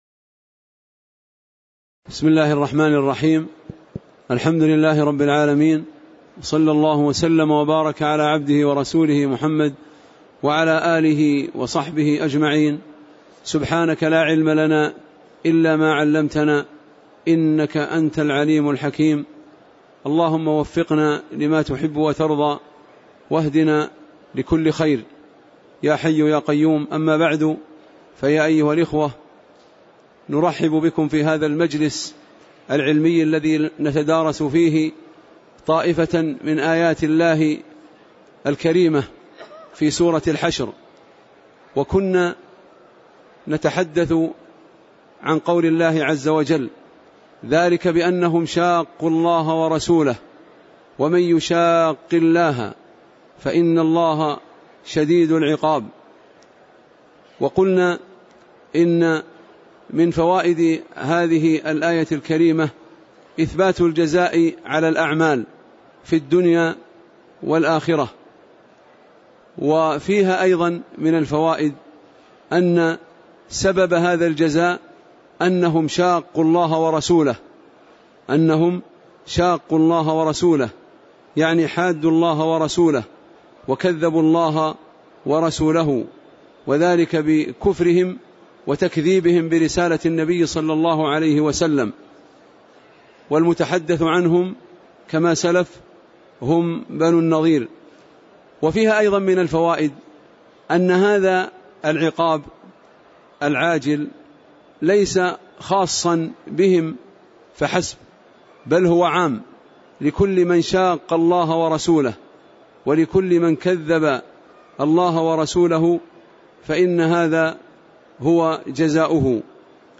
تاريخ النشر ٥ رجب ١٤٣٨ هـ المكان: المسجد النبوي الشيخ